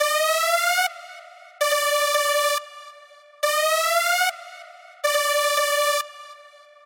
HBS S 嘻哈合成器 F6 D6 140BPM
Tag: 140 bpm Hip Hop Loops Synth Loops 1.15 MB wav Key : F